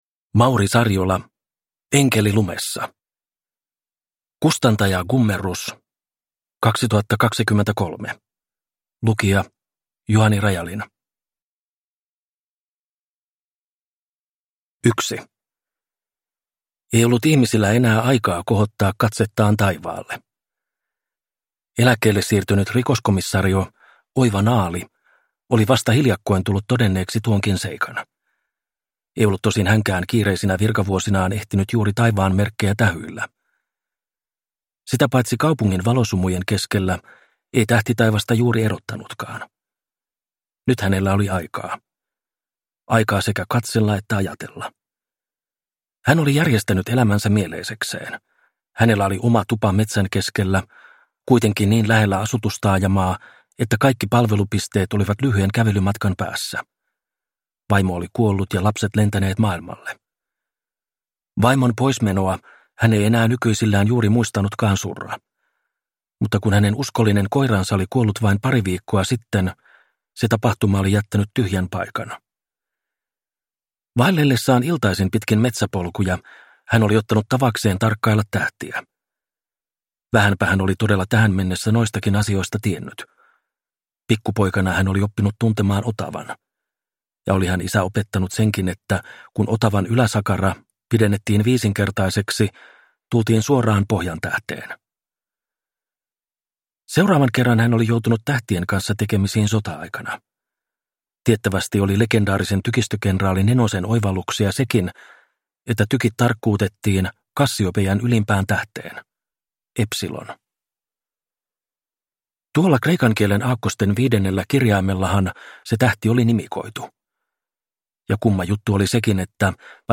Enkeli lumessa – Ljudbok – Laddas ner